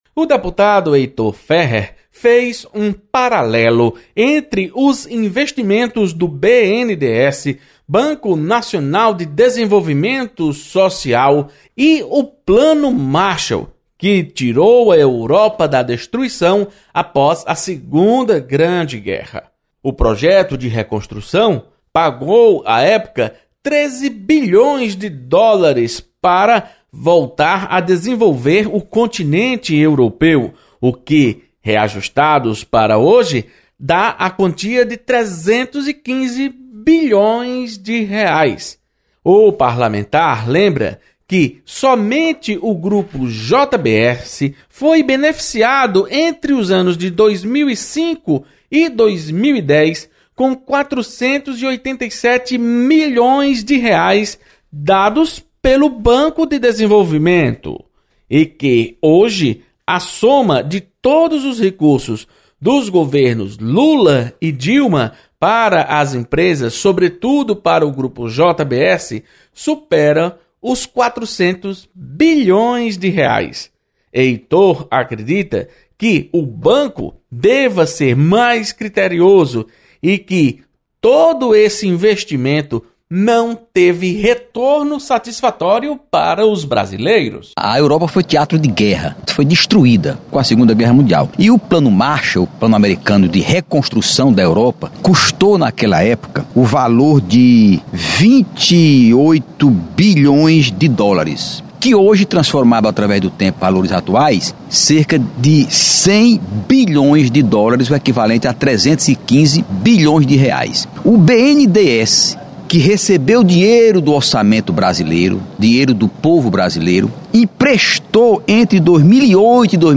Deputado Heitor Férrer critica financiamentos do BNDES.